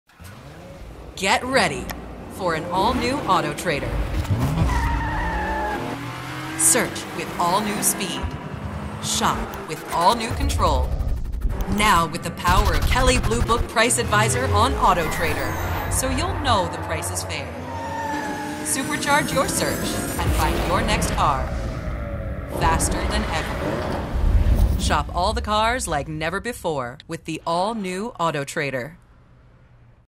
Female Voice Over, Dan Wachs Talent Agency.
Sassy, Dramatic, Conversational.
Automotive